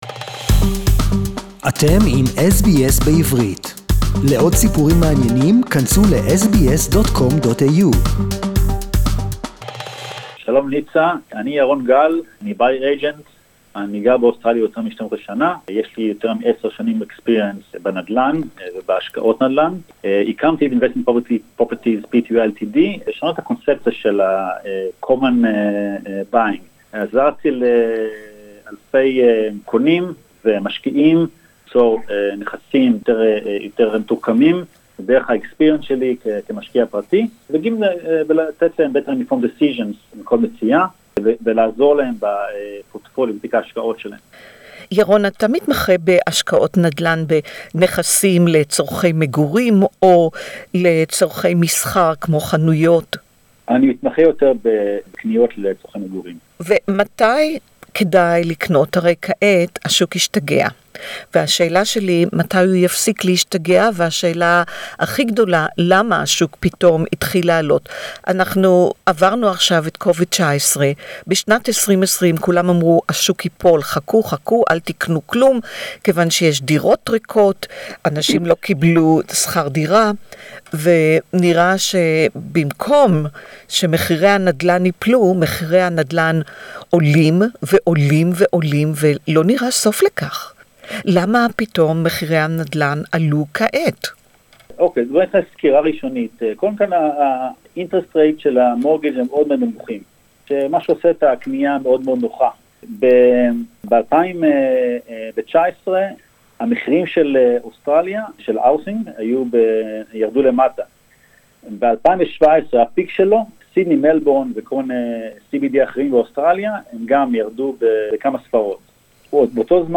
a Buyers’ agent to find out why and where and when (Hebrew interview)